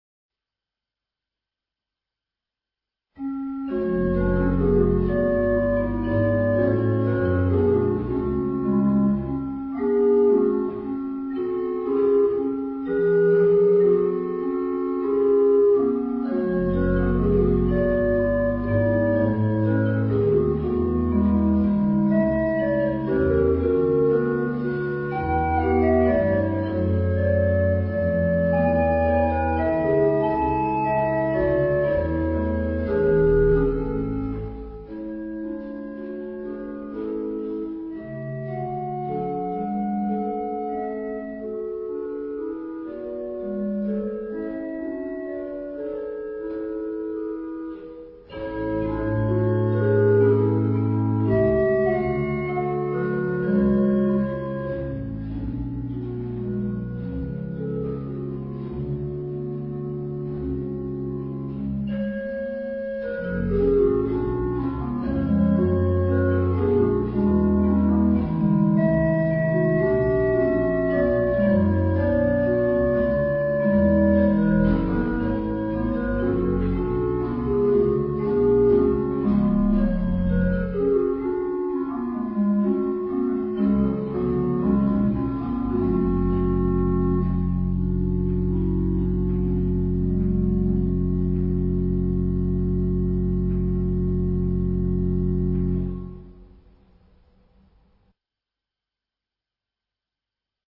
sonates pour orgue
sur l'orgue Silbermann-Stiehr de Bischwiller